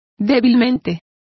Complete with pronunciation of the translation of weakly.